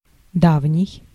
Ääntäminen
France: IPA: [lwɛ̃.t]